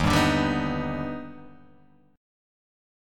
Eb+M7 chord